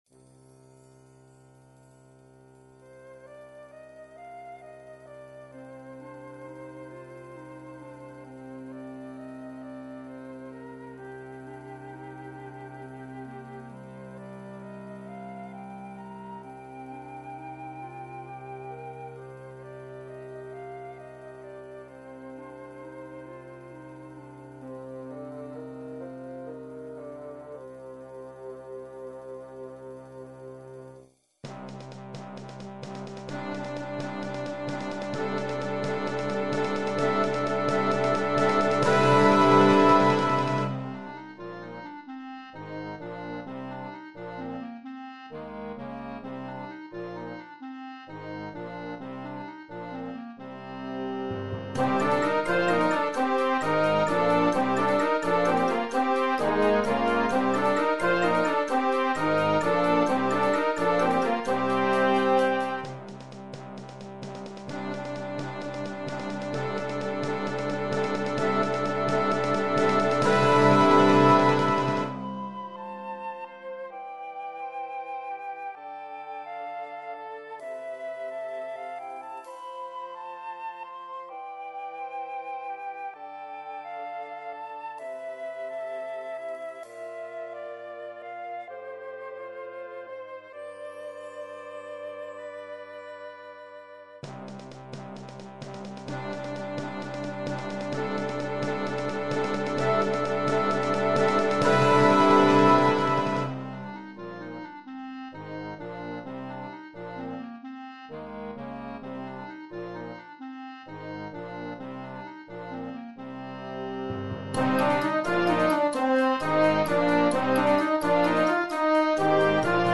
Per banda giovanile
fantasy for band